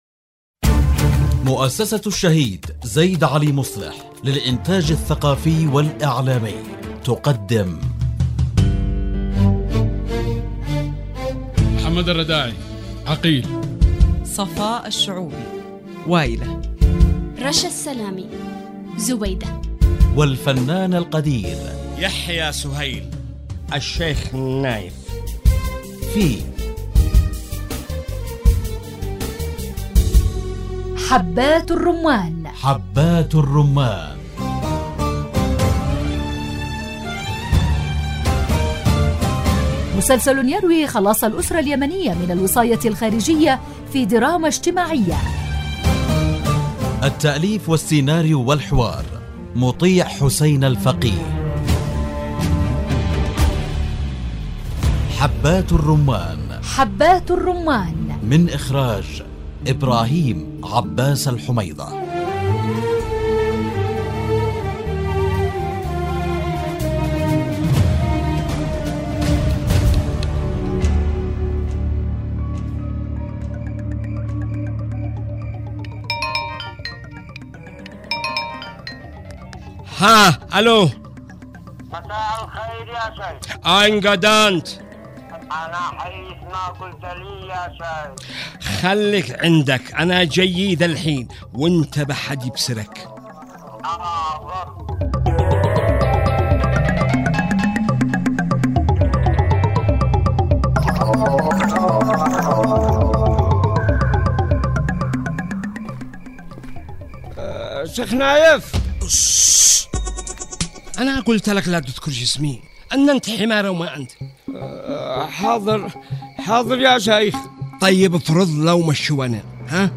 الدراما